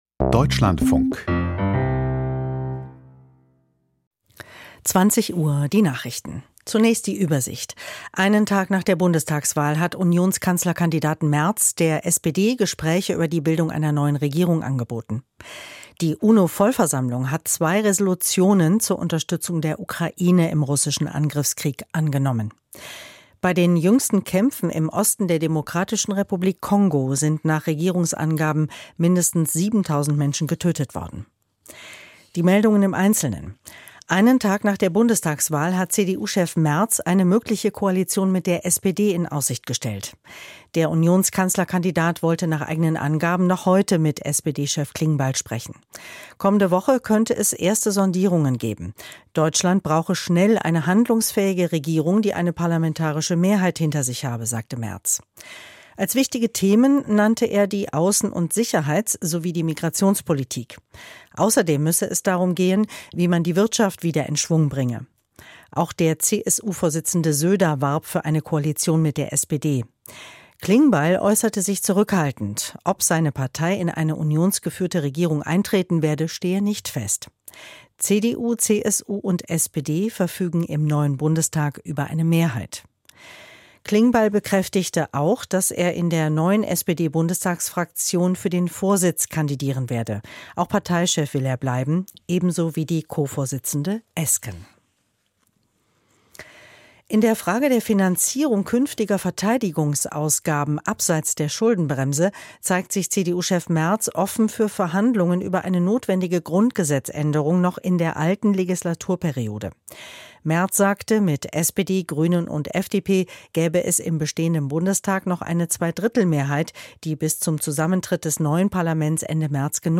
Die Deutschlandfunk-Nachrichten vom 24.02.2025, 20:00 Uhr